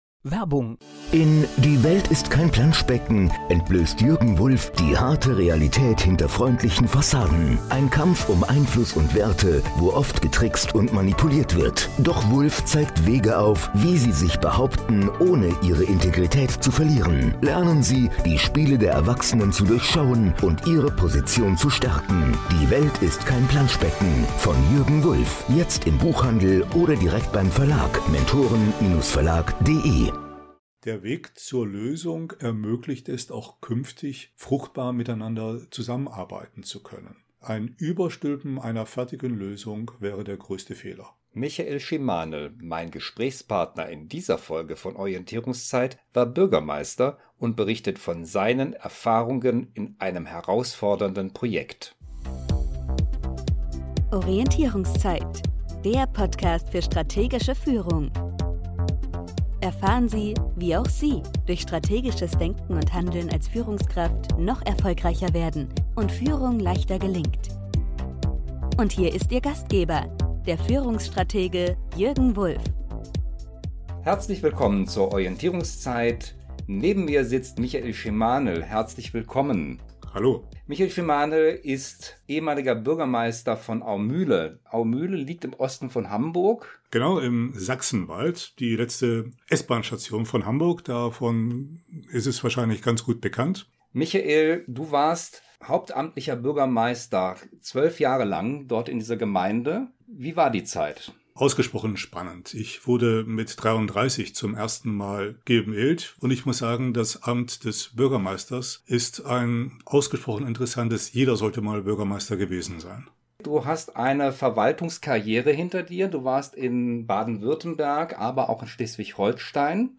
Interview mit Michael Schimanel ~ Orientierungszeit Podcast